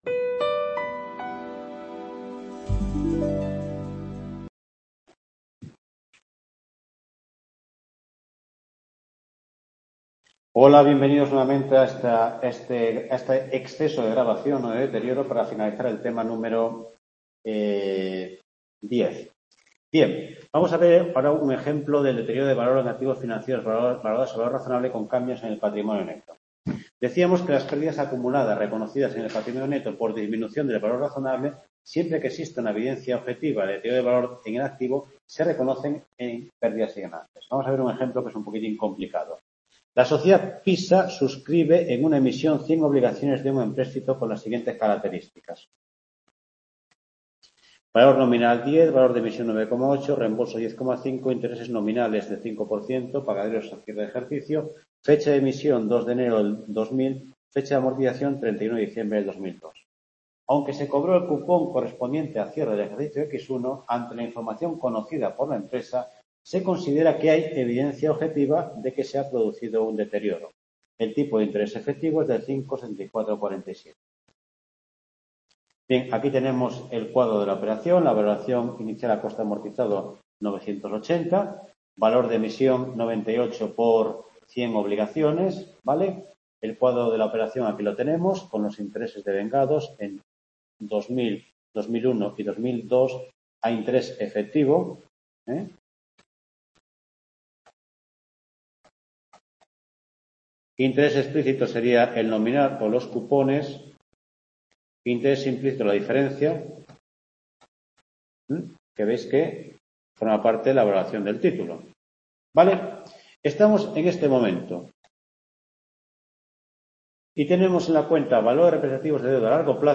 TUTORIA 10 (2ªparte)